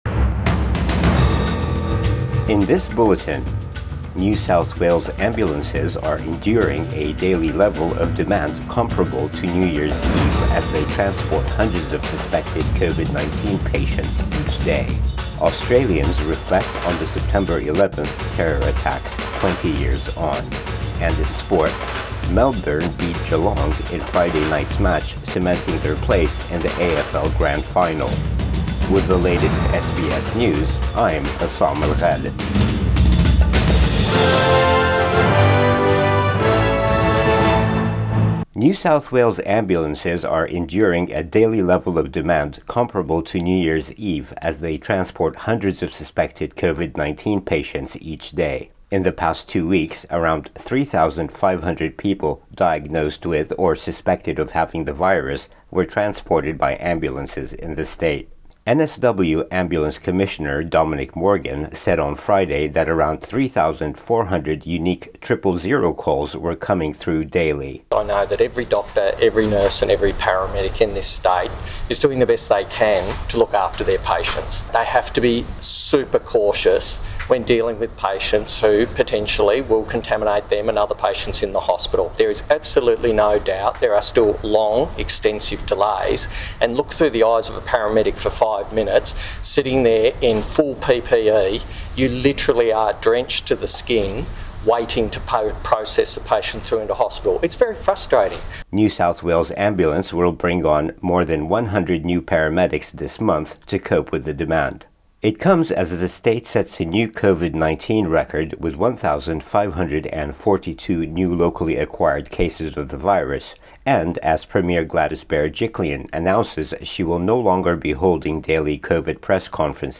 AM Bulletin 11 September 2021